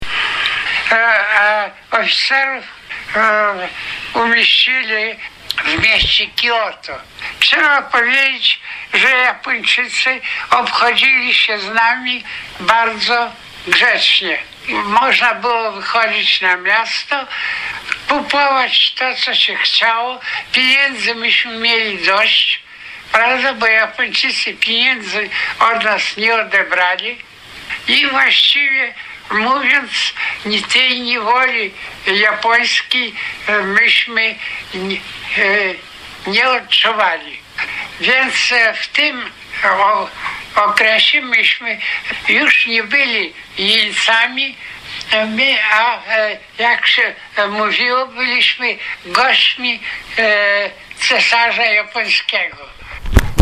Głos generała Jerzego Wołkowickiego.